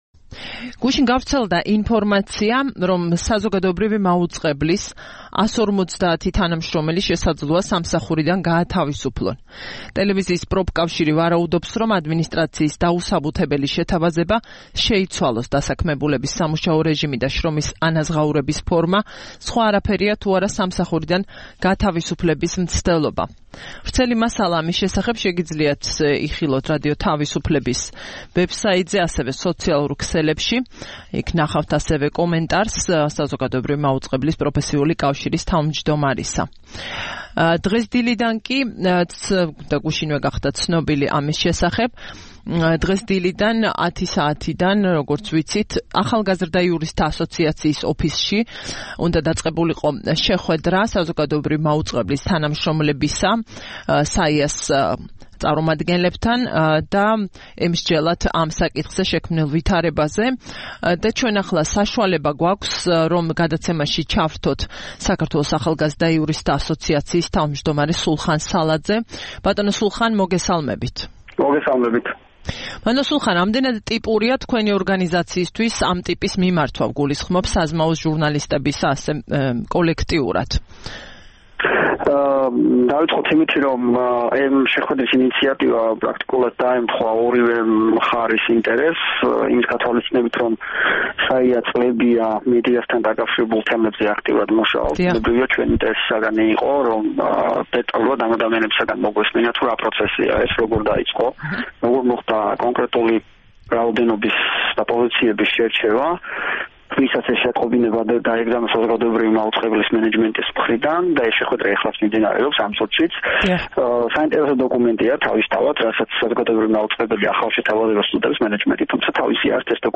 ტელეფონით ჩაერთო